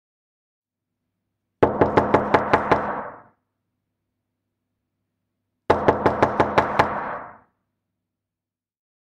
دانلود آهنگ در زدن 2 از افکت صوتی اشیاء
دانلود صدای در زدن 2 از ساعد نیوز با لینک مستقیم و کیفیت بالا
جلوه های صوتی